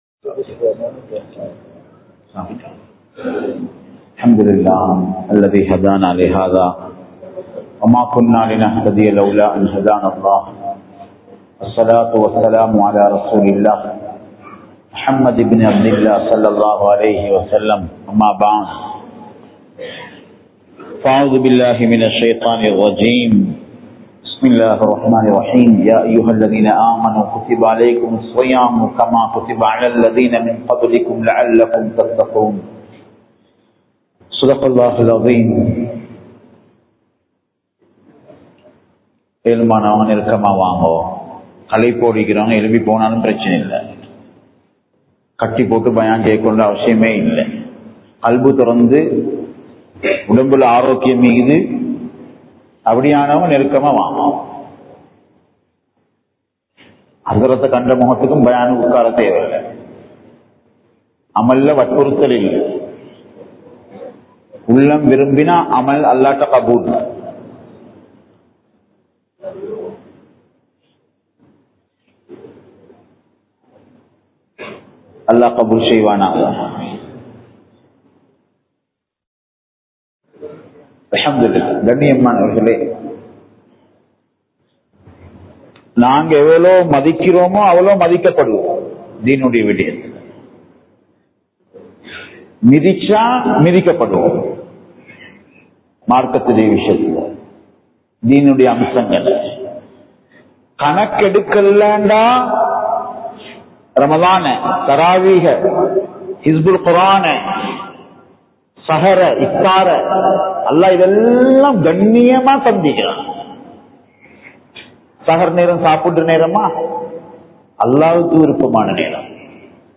ரமழானும் பாவமன்னிப்பும் | Audio Bayans | All Ceylon Muslim Youth Community | Addalaichenai
Colombo 12, Aluthkade, Muhiyadeen Jumua Masjidh